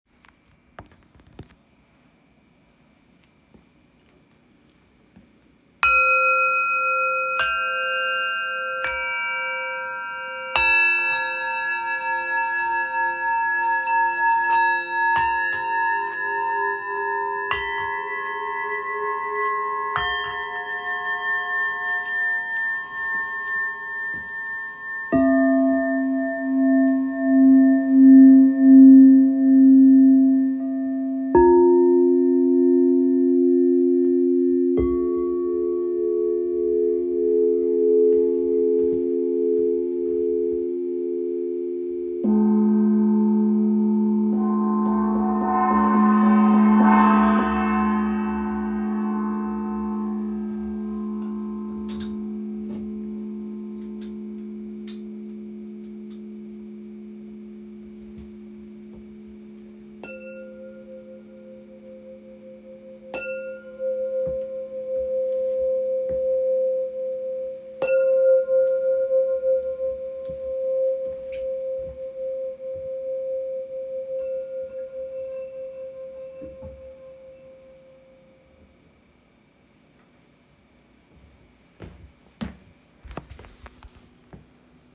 Singing Bowls
Listen to some of the bowls here -
Singing Bowls Demo
soundbells.m4a